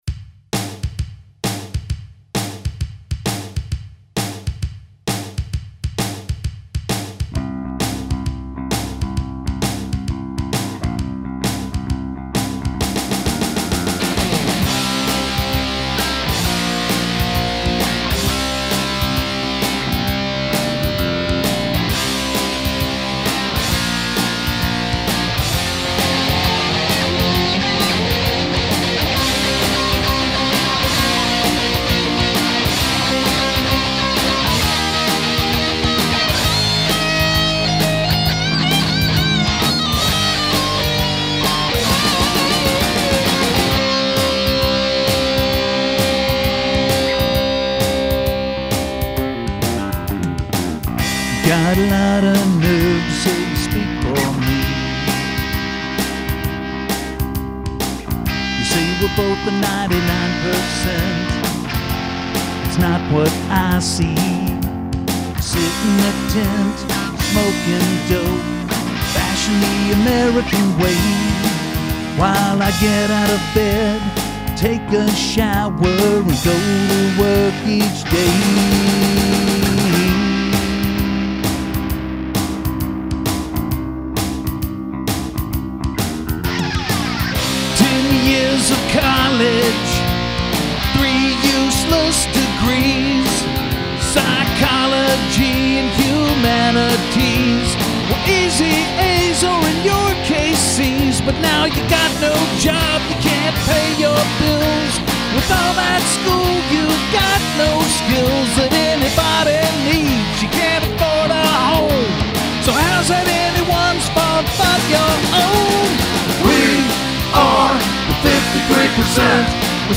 Conservative rock, Boise